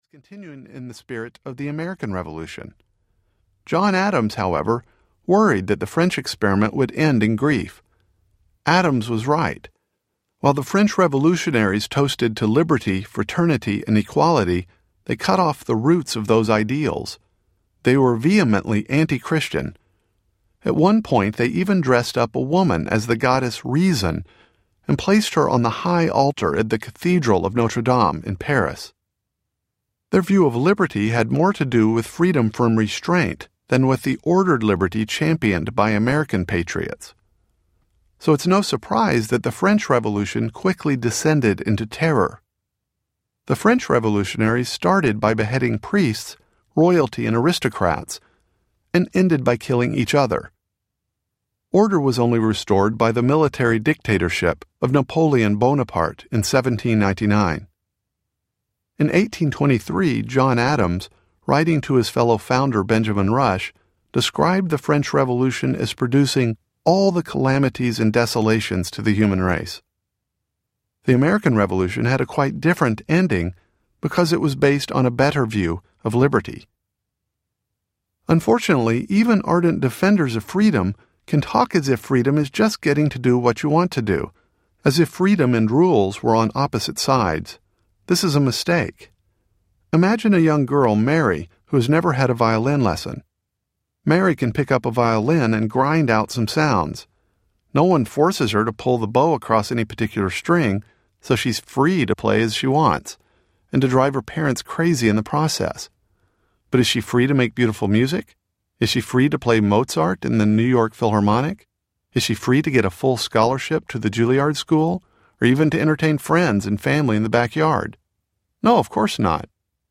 Indivisible Audiobook
11.5 Hrs. – Unabridged